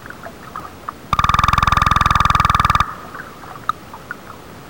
Cusk-eel sound pectrogram Spectrogram of Ophidion marginatum sounds recorded in Provincetown harbor, MA on 23 August 2001
cuskeel.wav